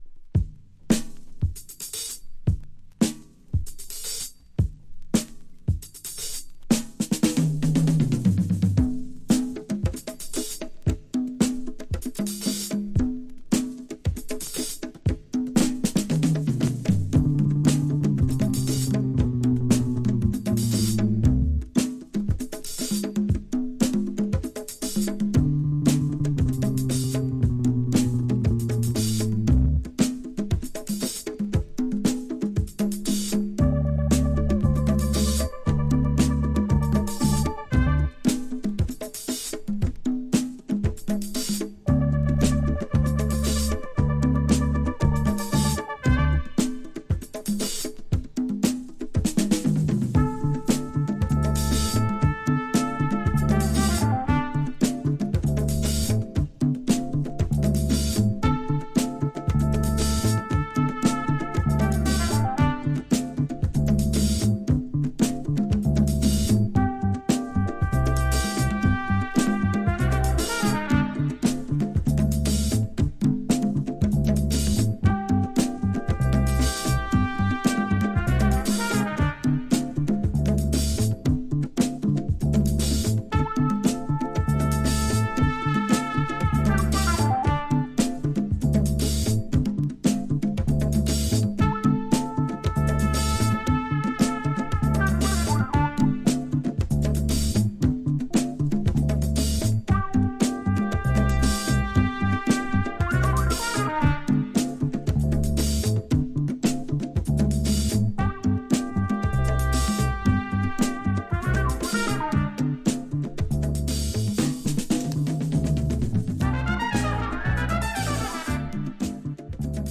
Tags: Cuba , Latin , Fusion